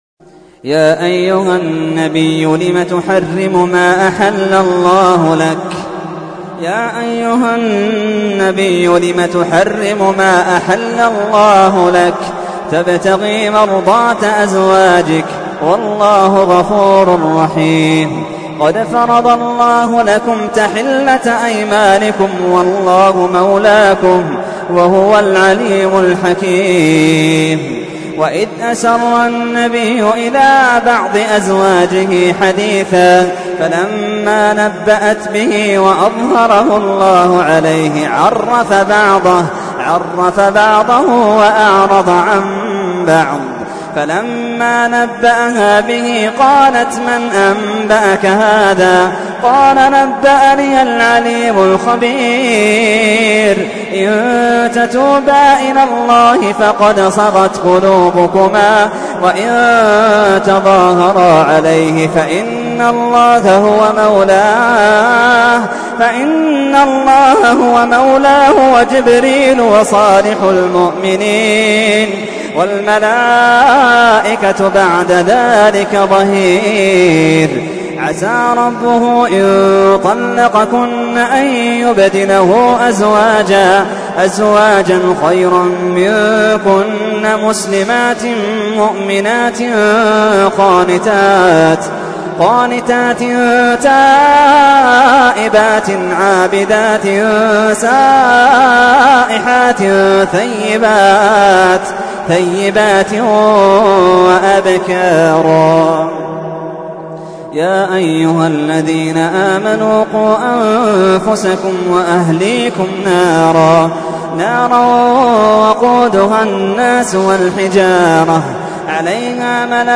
تحميل : 66. سورة التحريم / القارئ محمد اللحيدان / القرآن الكريم / موقع يا حسين